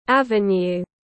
Avenue /ˈæv.ə.njuː/